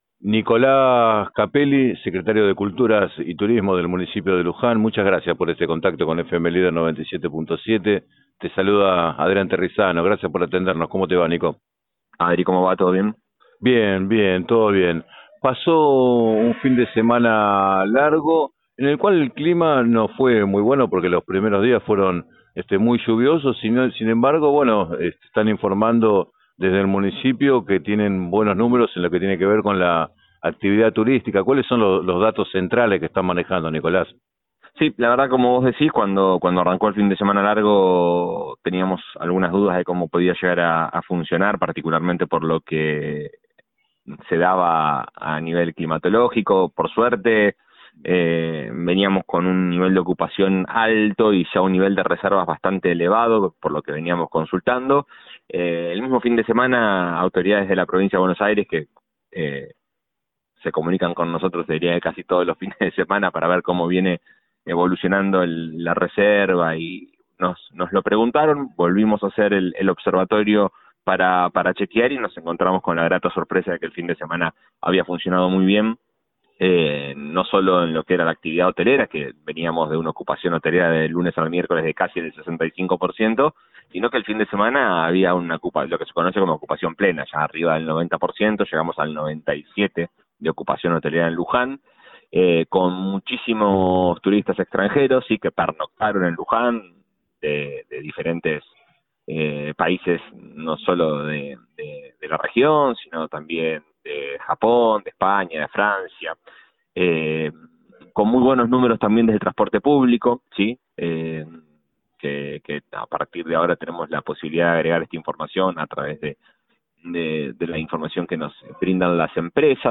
En declaraciones al programa 7 a 9 de FM Líder 97.7, Nicolás Capelli, secretario de Culturas y Turismo, informó que los visitantes se incrementaron en un 20 por ciento respecto de 2022 y destacó el impulso que brindó al turismo la cuarta edición del programa “Pre Viaje”.